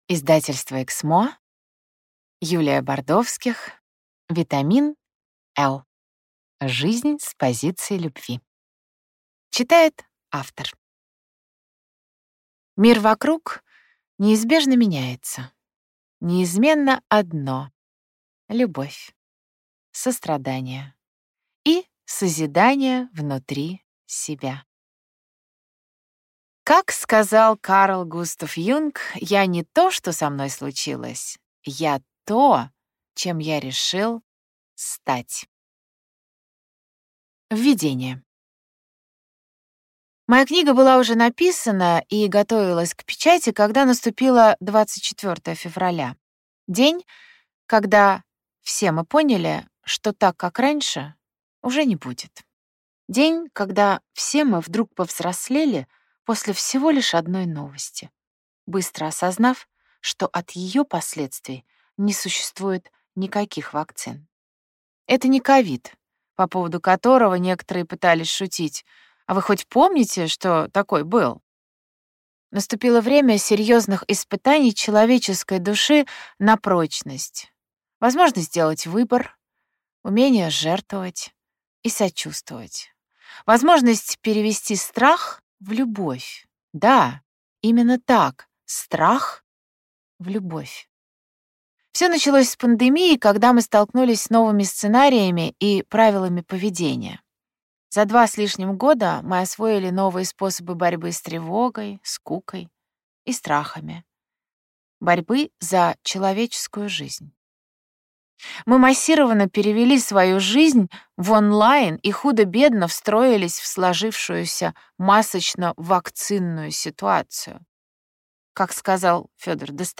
Аудиокнига Витамин L. Жизнь с позиции любви | Библиотека аудиокниг